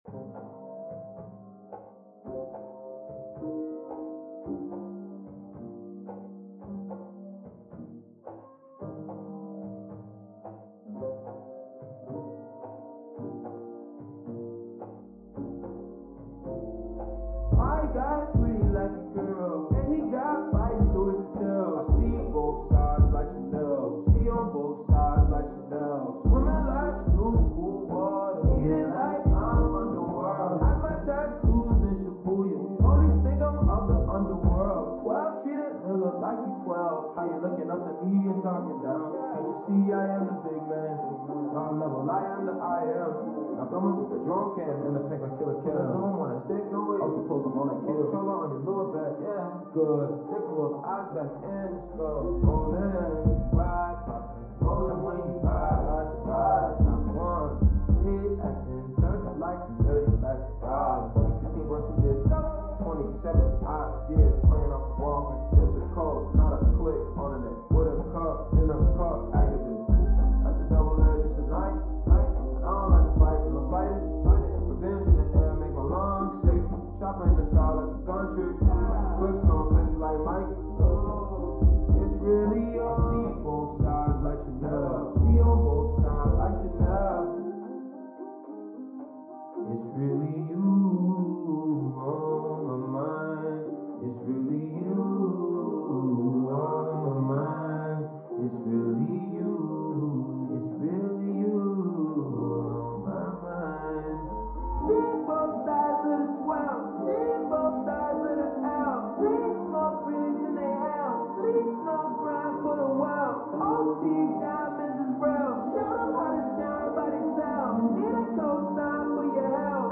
from another room remix